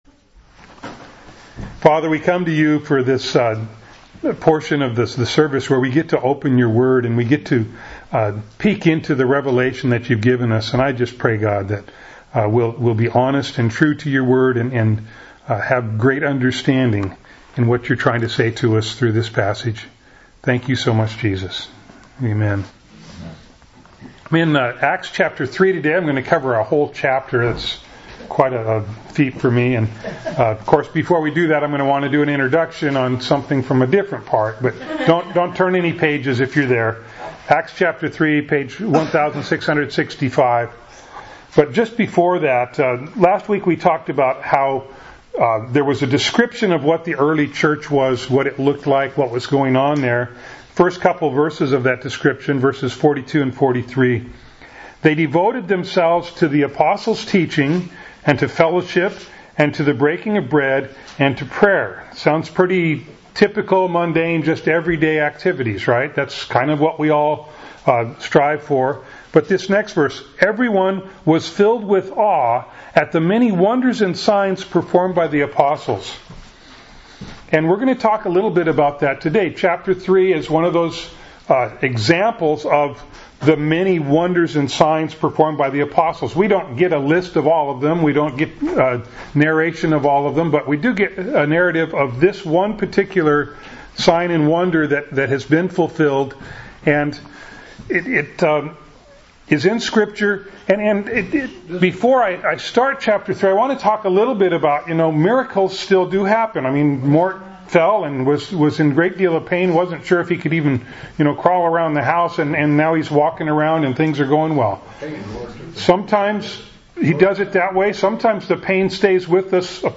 Acts 3:1-26 Service Type: Sunday Morning Bible Text